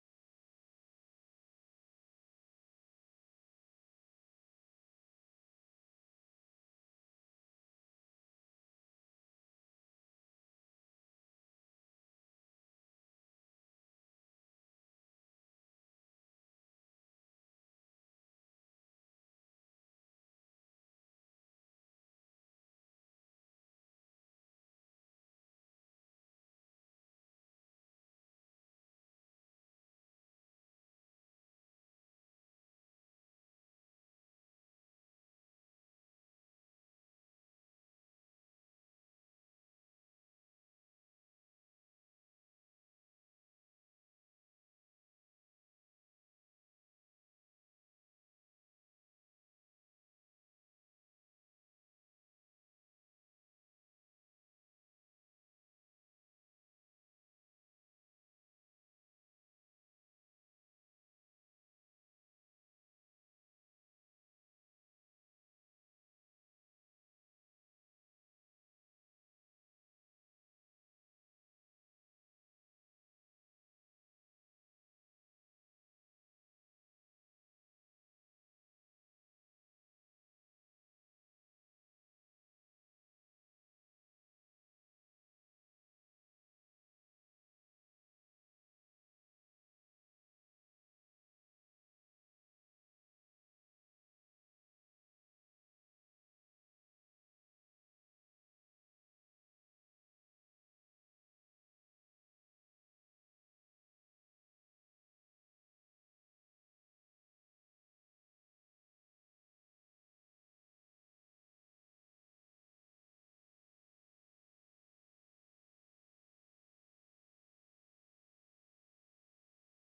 Listen Live - 생방송 듣기 - VOA 한국어